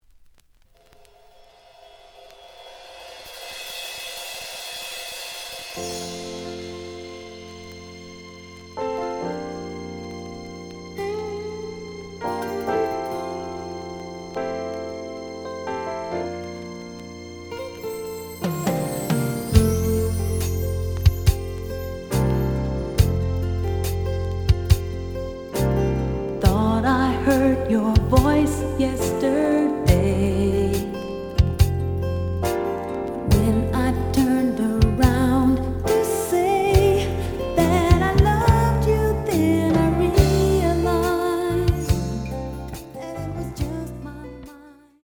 The audio sample is recorded from the actual item.
●Genre: Soul, 80's / 90's Soul
Slight edge warp. But doesn't affect playing. Plays good.)